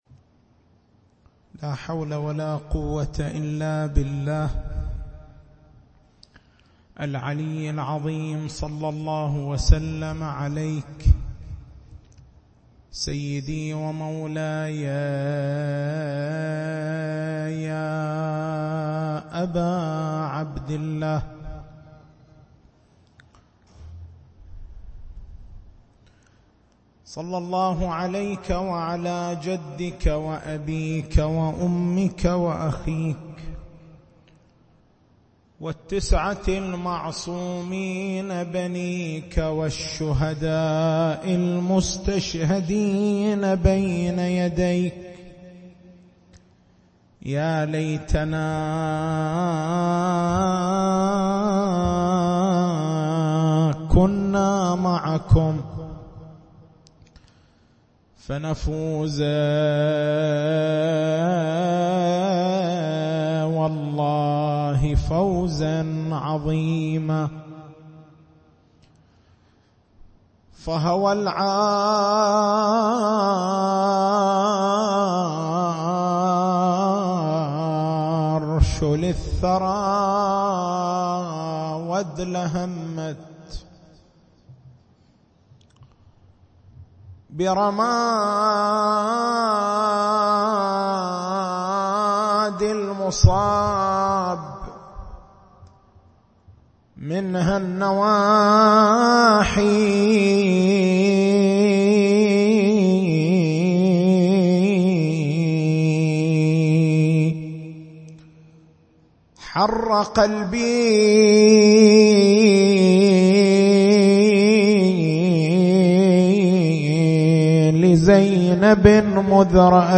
التسجيل الصوتي: اليوتيوب: شبكة الضياء > مكتبة المحاضرات > شهر رمضان المبارك > 1438